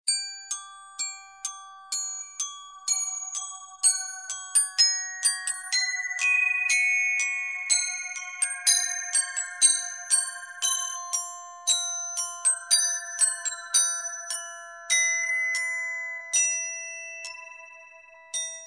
music-box-dead-battery_25389.mp3